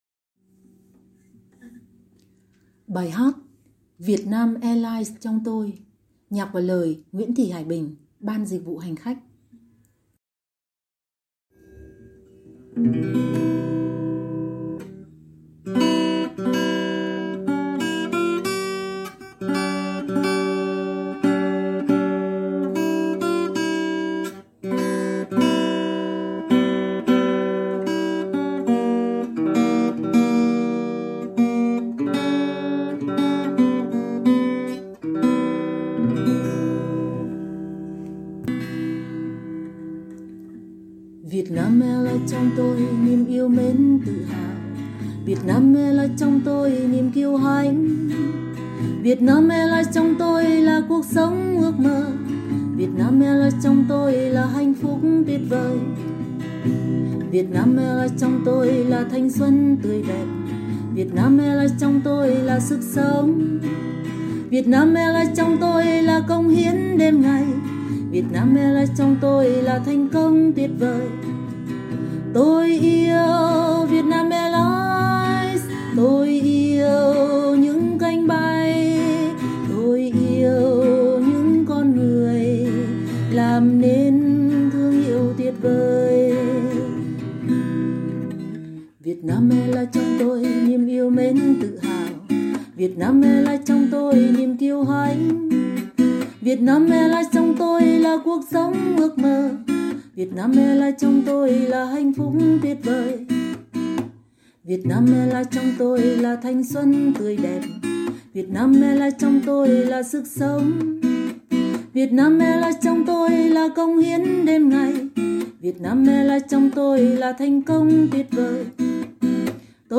Đặc biệt, đoạn Rap được thêm vào để mang hơi thở hiện đại và không khí sôi động cho màn trình diễn trong dịp kỷ niệm.